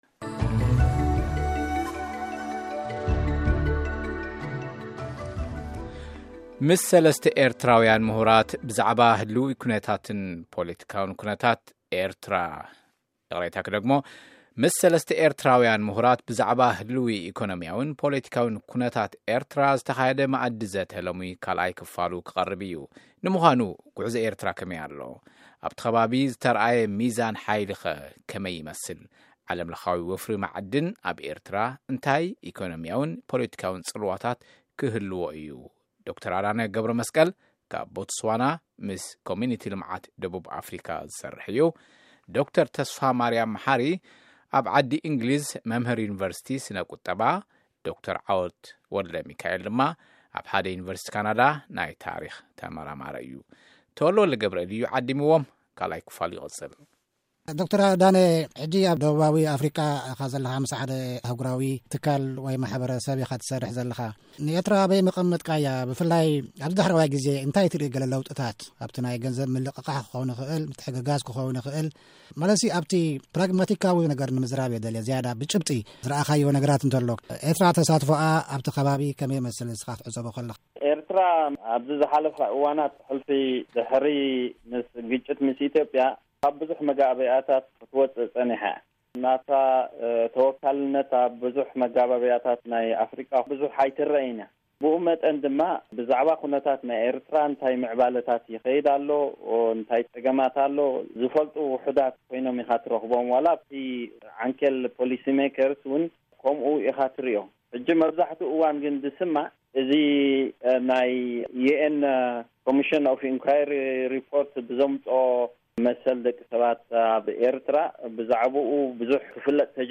ድምጺ ኣመሪካ ንሰለስተ ኤርትራውያን ምሁራት ብዛዕባ ህልው ኤኮኖሚያውን ፖለቲካውን ኩነታት ኤርትራ ኣዘራሪብዎም ኣሎ። ንምዃኑ ጉዕዞ ኤርትራ ከመይ ኣሎ?